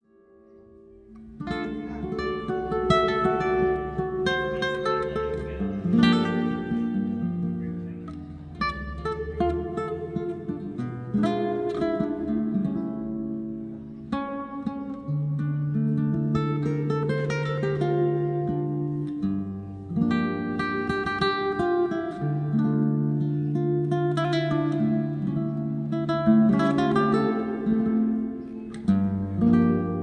Live in Little Tokyo
Solo Guitar Standards
Soothing and Relaxing Guitar Music